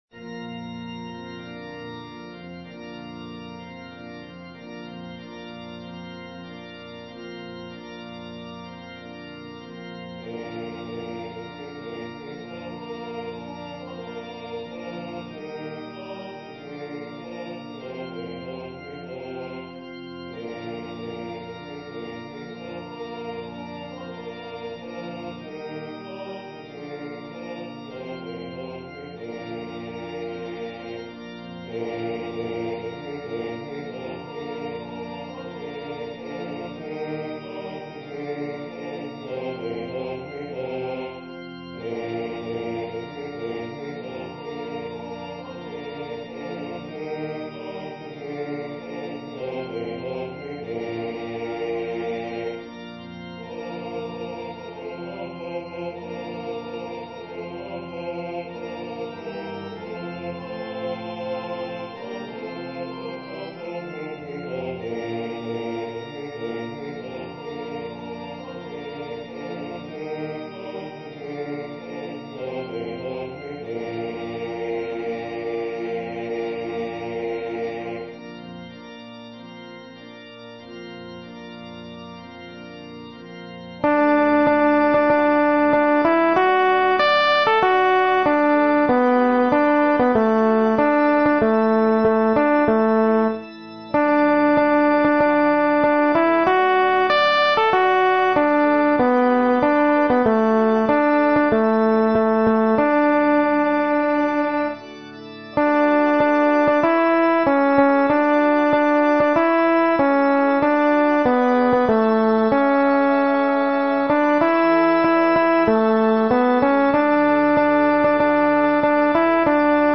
アルト2（フレットレスバス音）